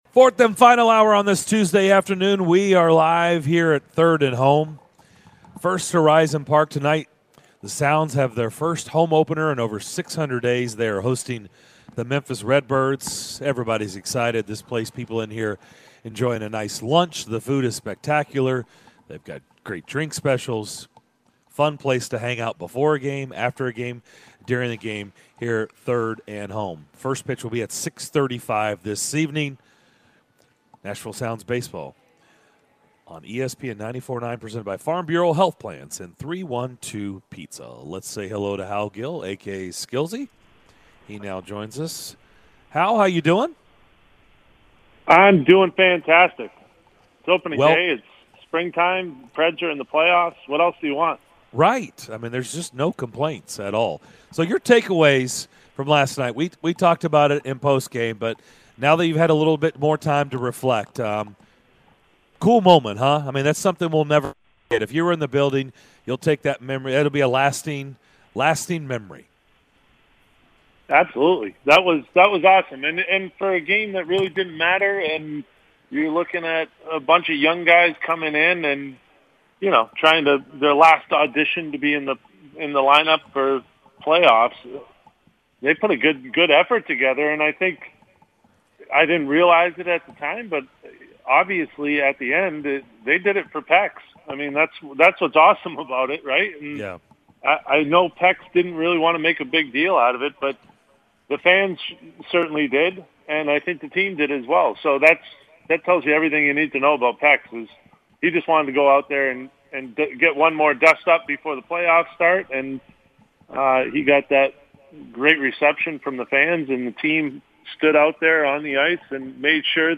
Preds Radio Analyst Hal Gill joined DDC to give his thoughts on Pekka Rinne's big night and the Preds chances to pull off the upset in the upcoming playoff series vs Carolina!